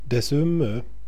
Uttal